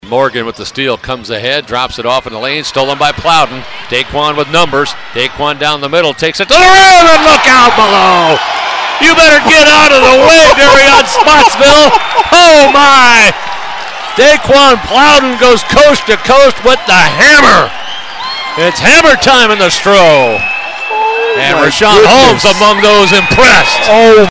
radio call